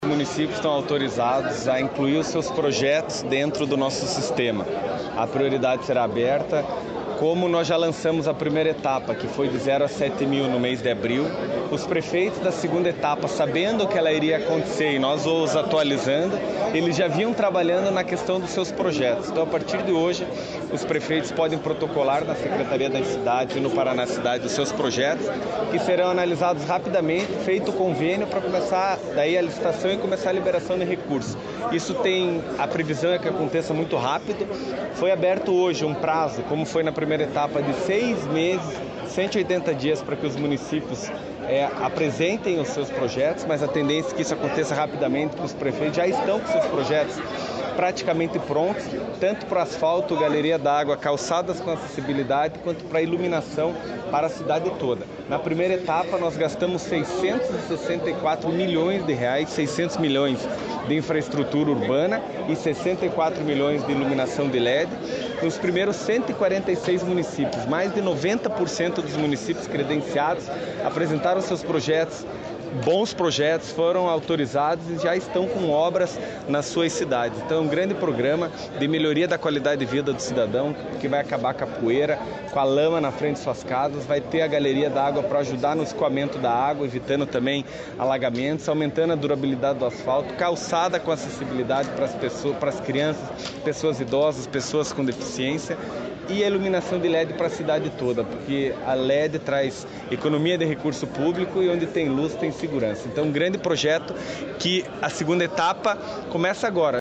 Sonora do prefeito em exercício de Curitiba e secretário das Cidades, Eduardo Pimentel, sobre a nova fase do programa Asfalto Novo, Vida Nova